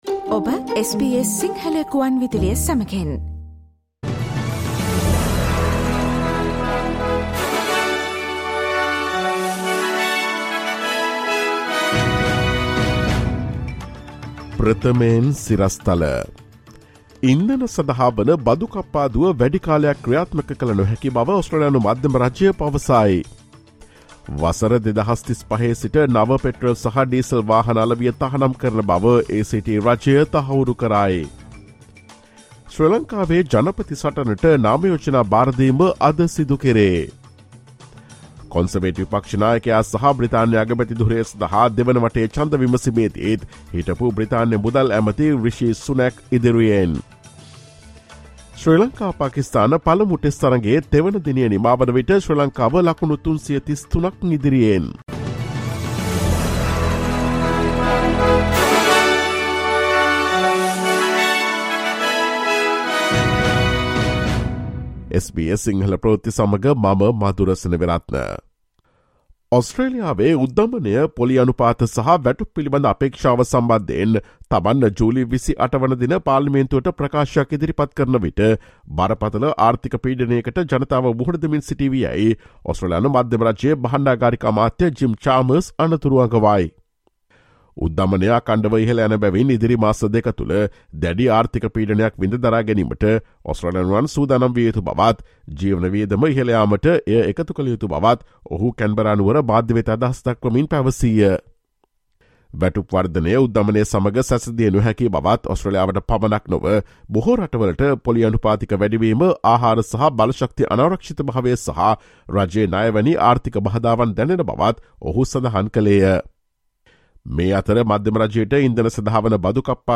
ඔස්ට්‍රේලියාවේ සහ ශ්‍රී ලංකාවේ නවතම පුවත් මෙන්ම විදෙස් පුවත් සහ ක්‍රීඩා පුවත් රැගත් SBS සිංහල සේවයේ 2022 ජූලි 19 වන දා අඟහරුවාදා වැඩසටහනේ ප්‍රවෘත්ති ප්‍රකාශයට සවන් දීමට ඉහත ඡායාරූපය මත ඇති speaker සලකුණ මත click කරන්න.